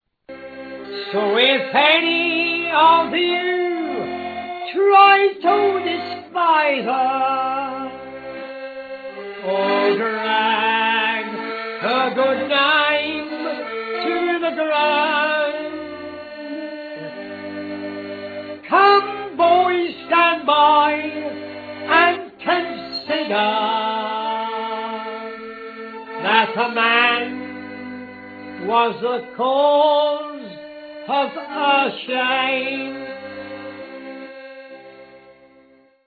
With his compelling strangled delivery of the song and outrageous melodeon accompaniment, he sounds like a minor league Davy Stewart.